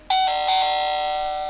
toydoorbell
analog ding dong door-bell toy sound effect free sound royalty free Sound Effects